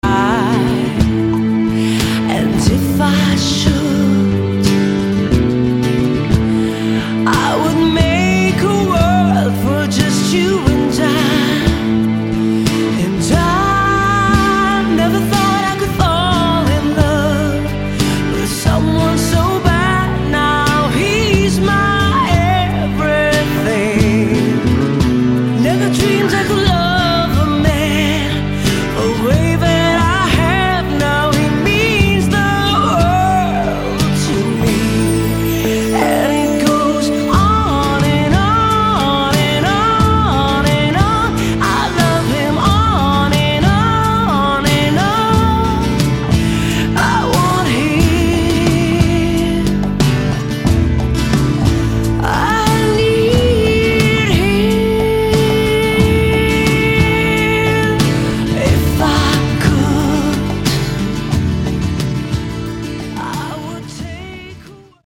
hard-driving energy
classic female fronted rock sound